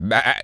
goat_baa_calm_09.wav